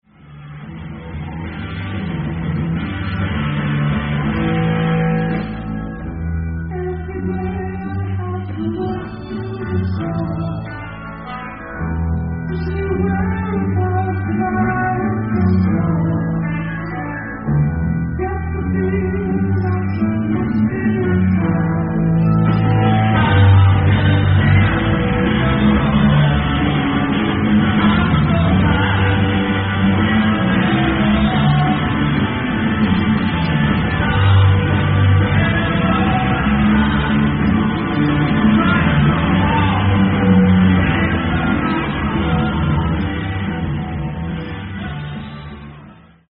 Los Angeles, California
Venue: Wiskey A Go-Go
Source:  Audience Recording